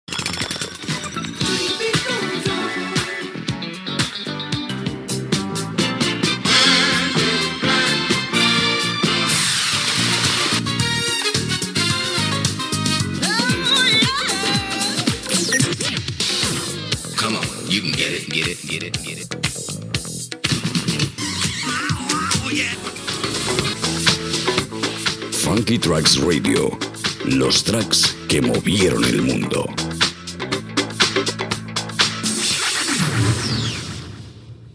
Mescla musical i indicatiu de la ràdio